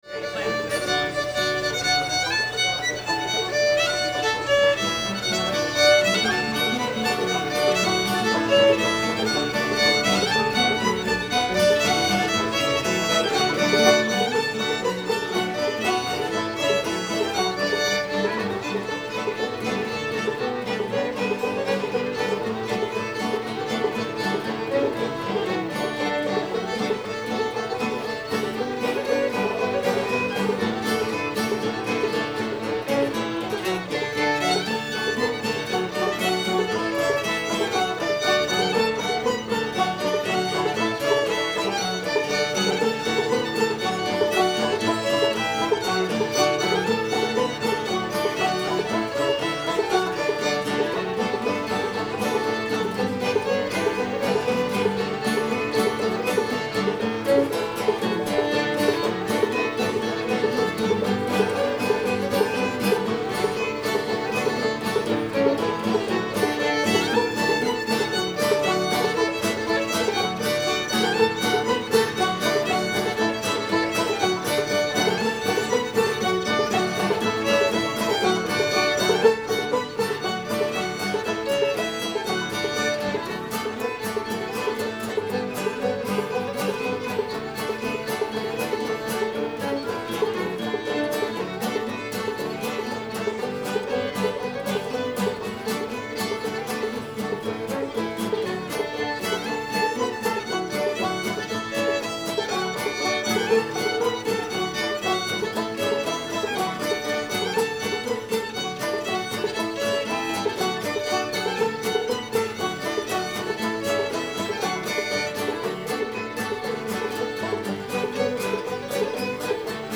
cheat mountain [D]